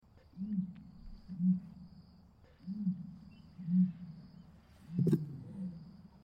На этой странице вы найдете подборку звуков страуса – от характерного шипения до необычных гортанных криков.
Звуки африканского страуса (ostrich)